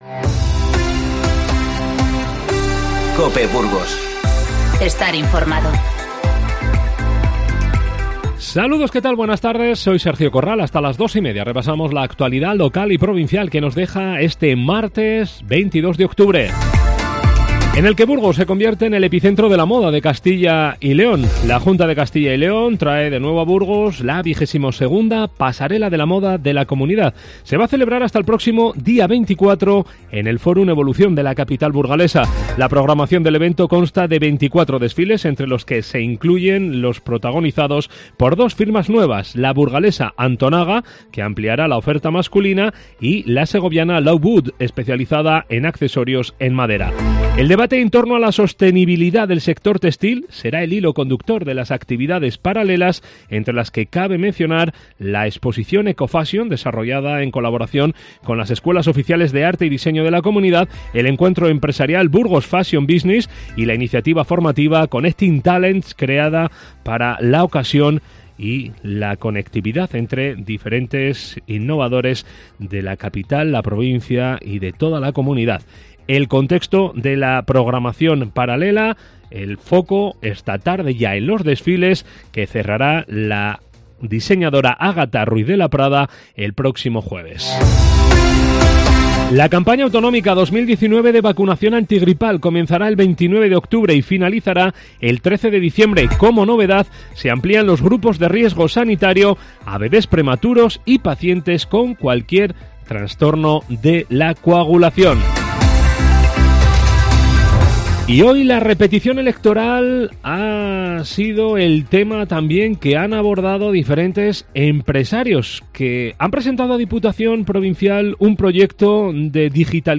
INFORMATIVO Mediodía 22-10-19